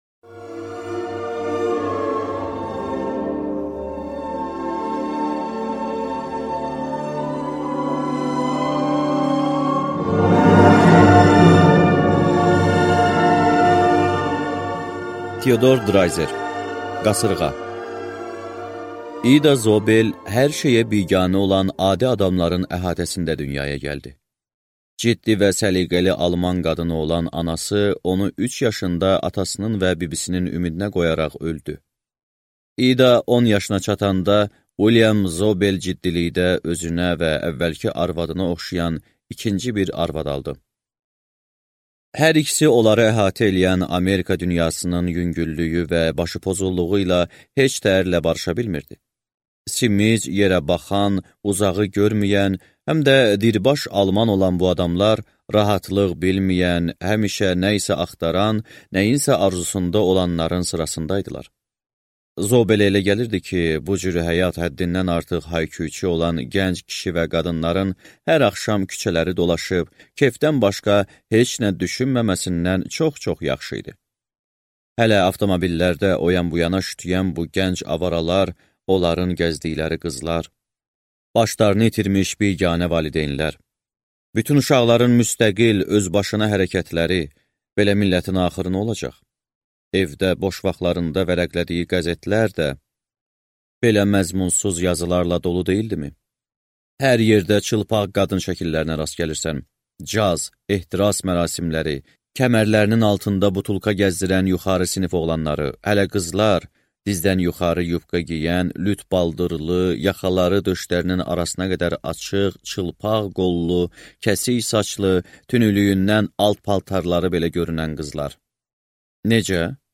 Аудиокнига Qasırğa | Библиотека аудиокниг